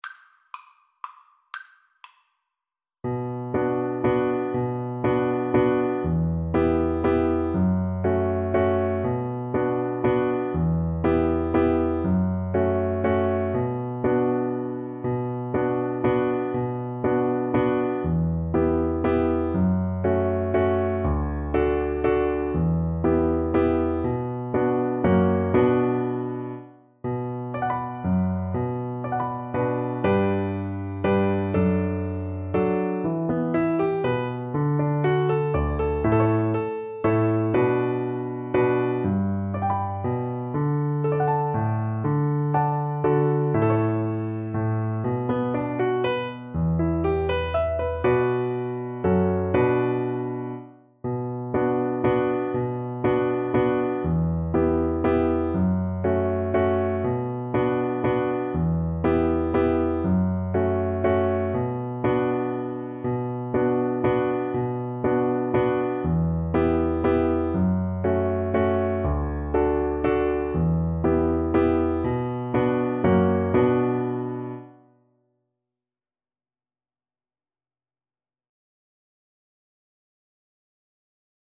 = 120 Allegro (View more music marked Allegro)
3/4 (View more 3/4 Music)
Traditional (View more Traditional Alto Recorder Music)